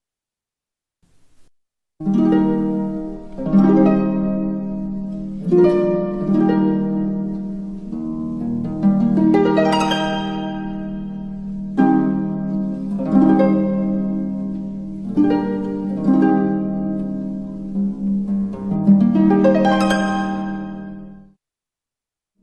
arpa.mp3